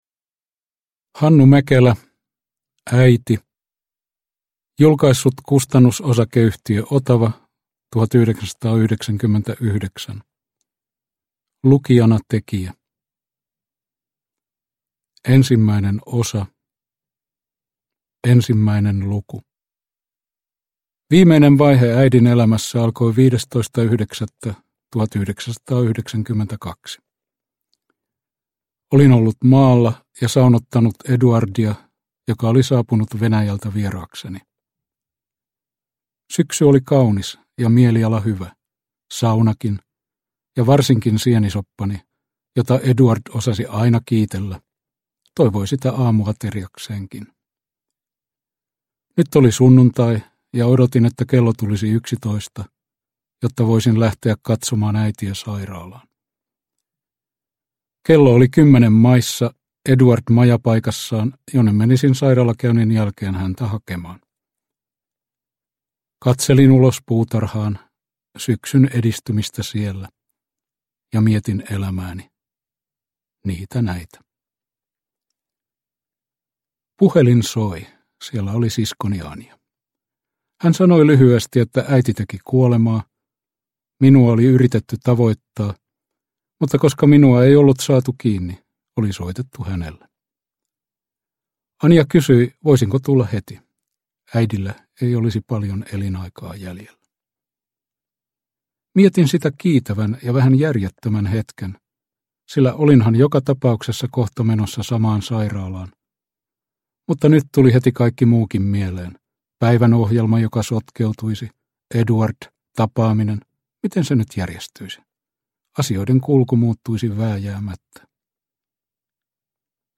Äiti – Ljudbok – Laddas ner
Uppläsare: Hannu Mäkelä